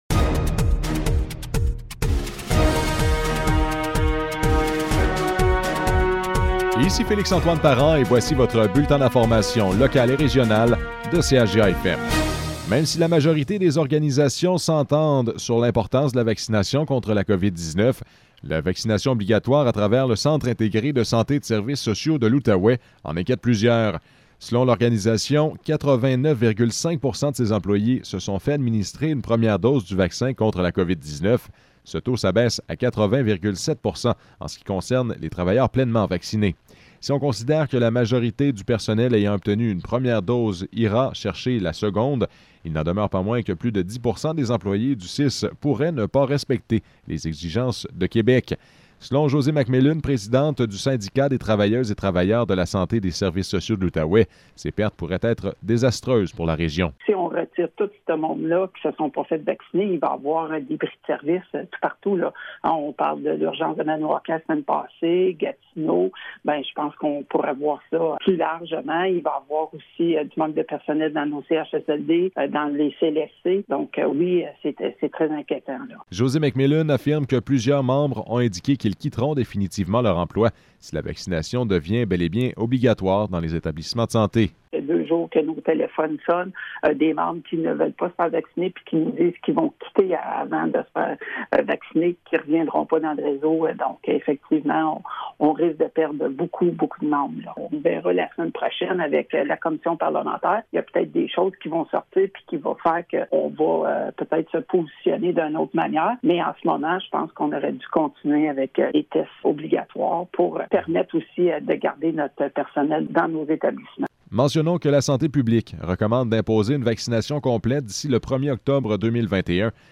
Nouvelles locales - 19 août 2021 - 12 h